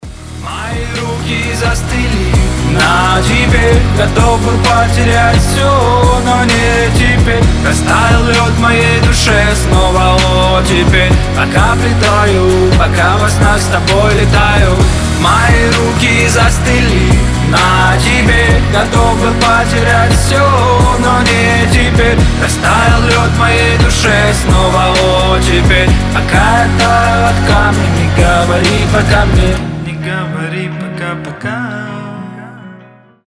красивые
сильные